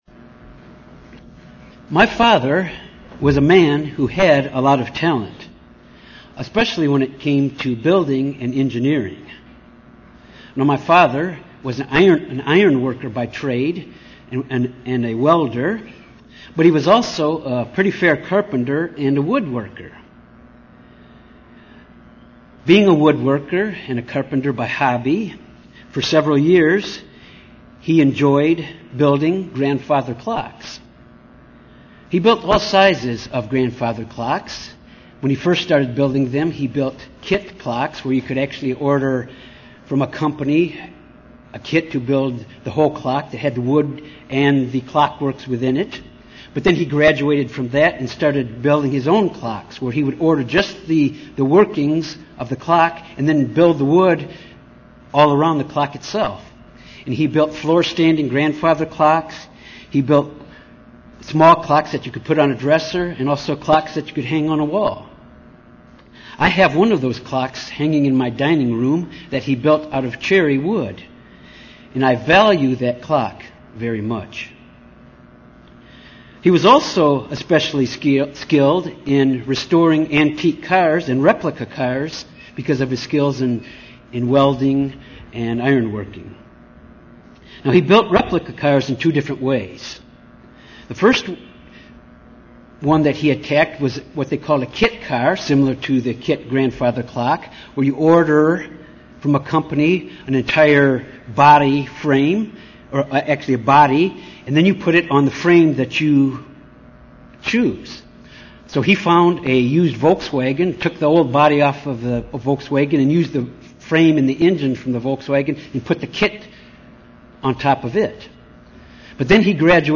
Given in Little Rock, AR
UCG Sermon Studying the bible?